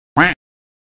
quack